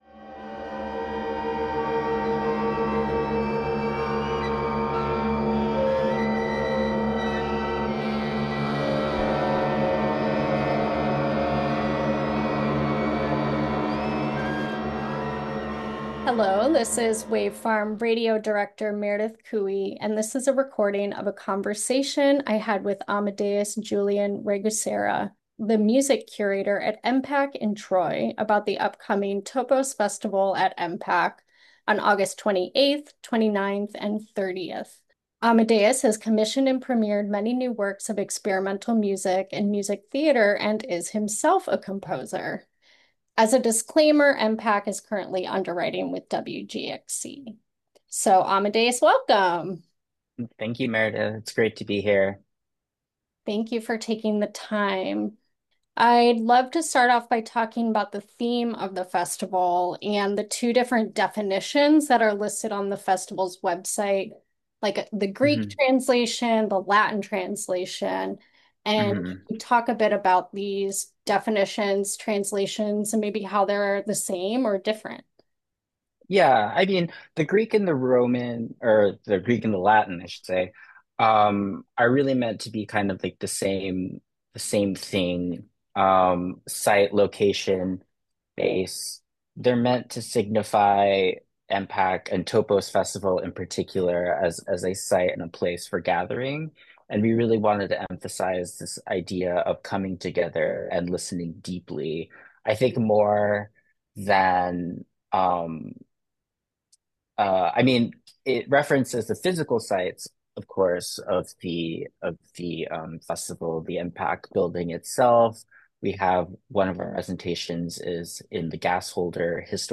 Interview starts at 00:00:00 After the weather and news